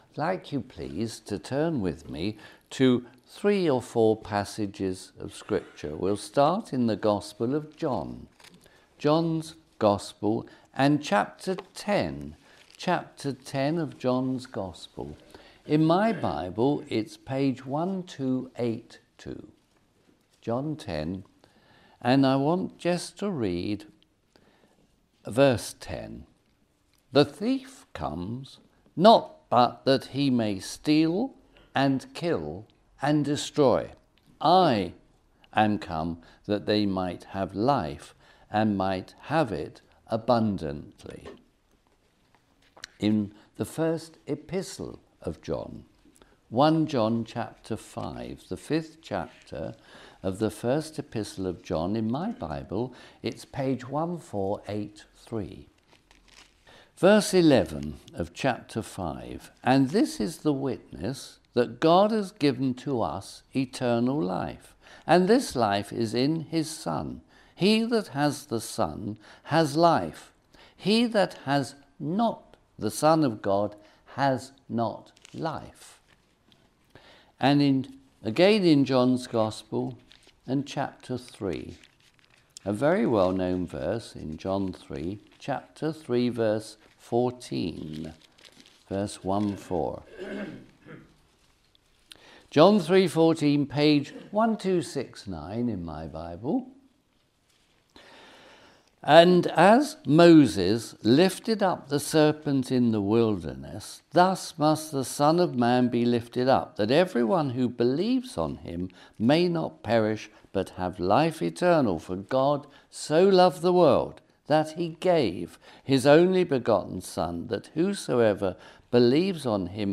A clear and urgent gospel message asking the most important question: Do you have eternal life? Find hope, truth, and the way to salvation through Jesus Christ.